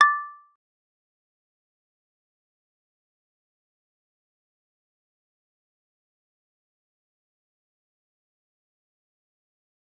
G_Kalimba-D7-mf.wav